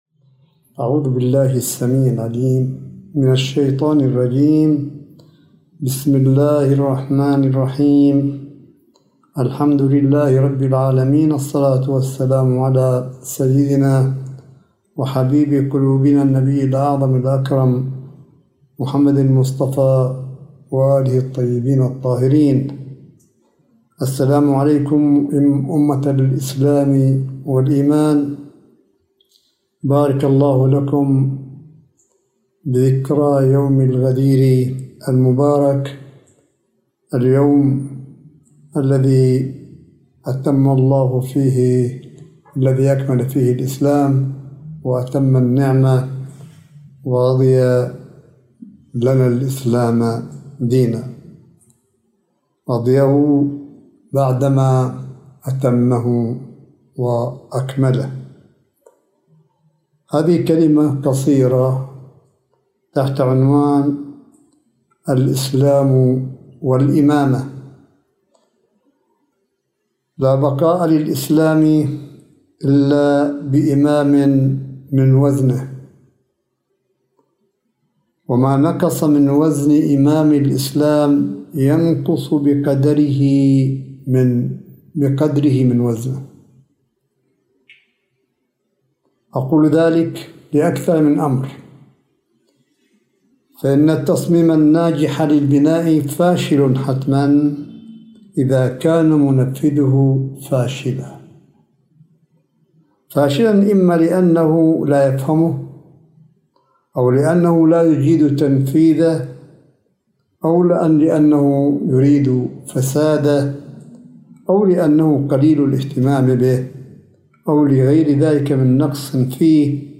ملف صوتي لكلمة آية الله الشيخ عيسى قاسم بمناسبة عيد الغدير الأغر ١٤٤١هـ / ٠٨ اغسطس ٢٠٢٠م